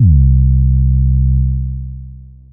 TM88 Tune808.wav